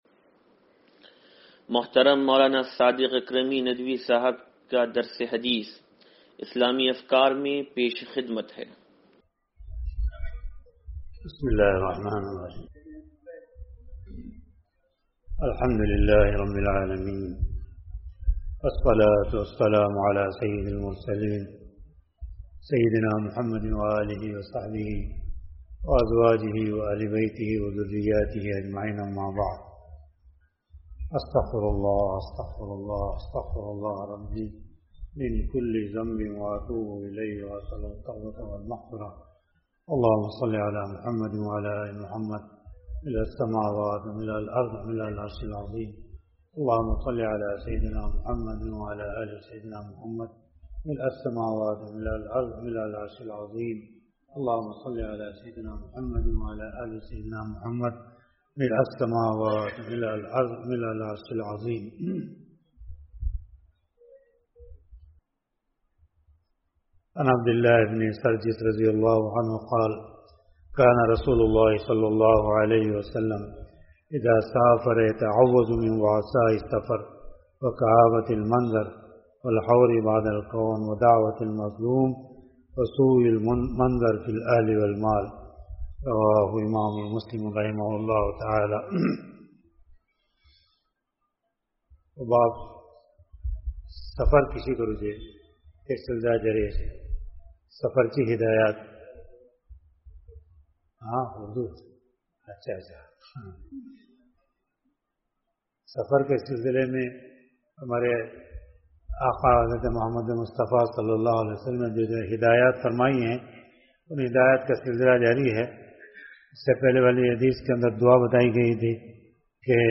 درس حدیث نمبر 0759